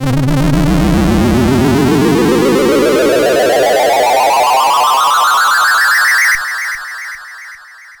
Scary
Just another cheap computer game effect which gets use of the ringmodulator. The ringmod multiplies the output of two oscillators, the resulting waveform is enriched by non-harmonic overtones.
• enable the Triangle waveform for OSC1 and OSC2, and set the "ring" flag of OSC2. Now the output of OSC2 is ringmodulated by OSC1
mbsid_tutor_scary.mp3